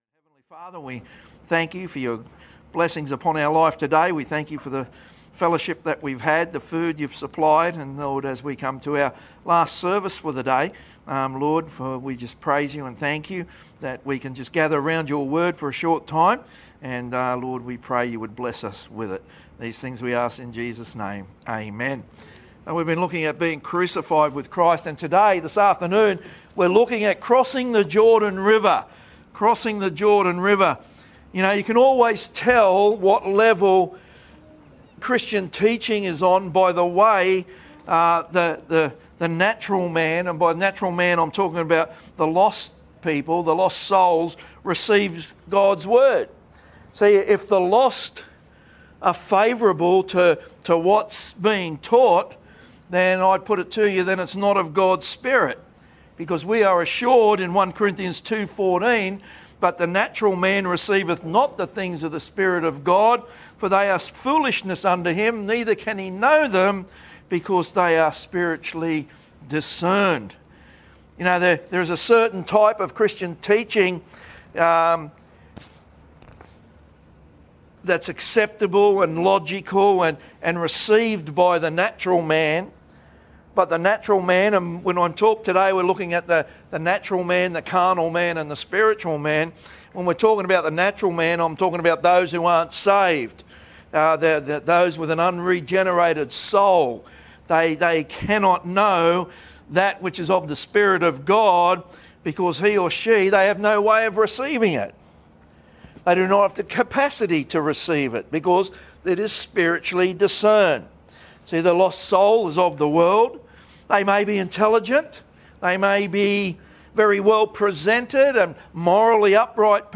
PM sermon on the 21st of September 2025 at the Gold Coast